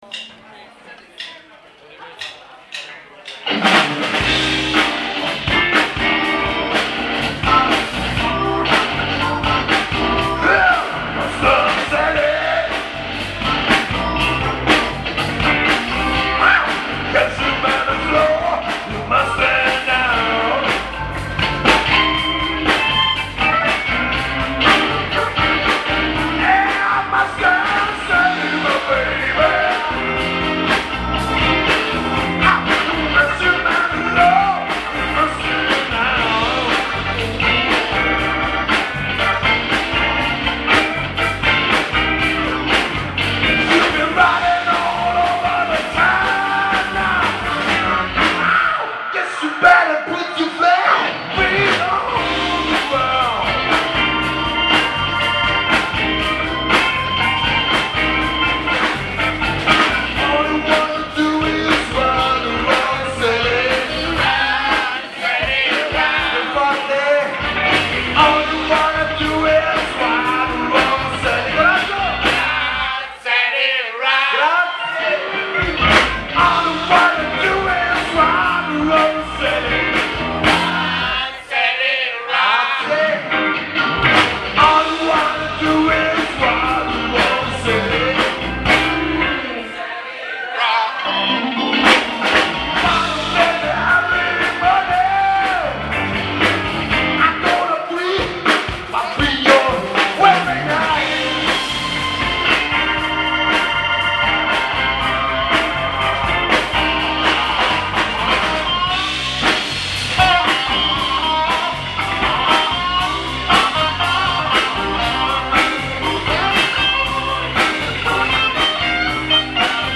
Funnel Pub - 16 gennaio 2010